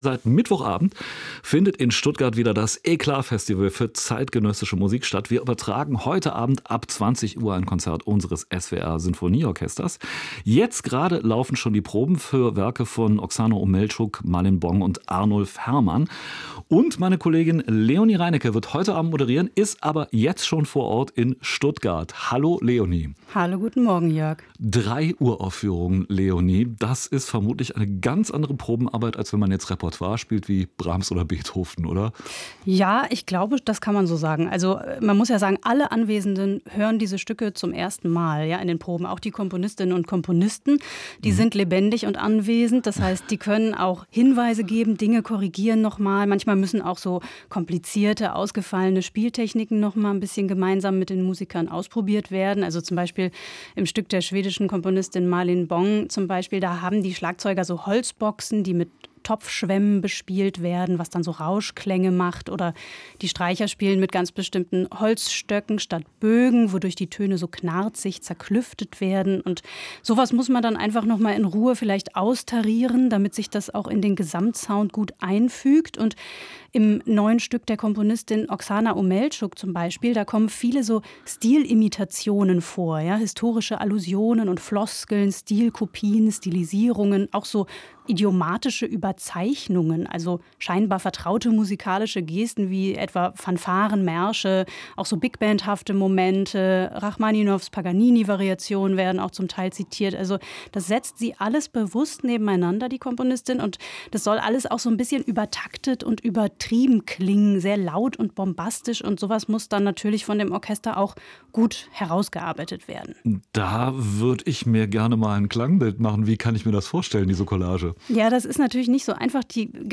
Musikgespräch
Gespräch mit